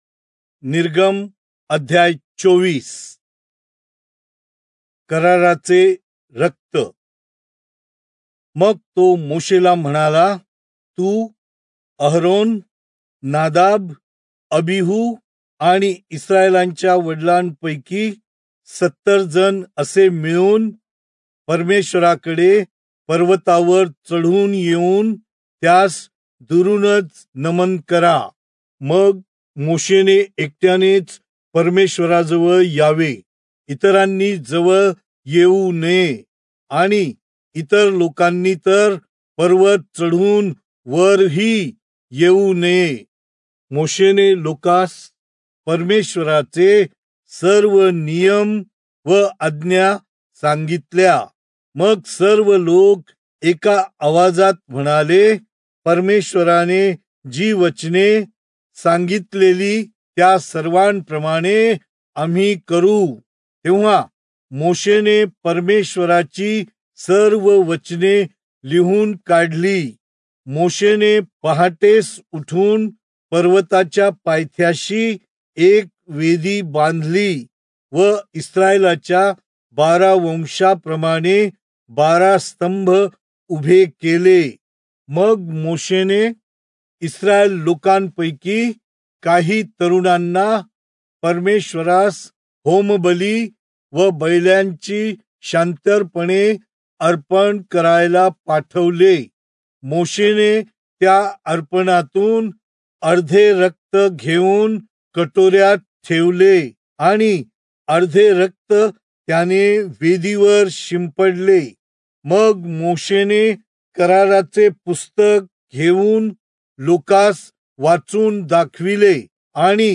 Marathi Audio Bible - Exodus 33 in Irvmr bible version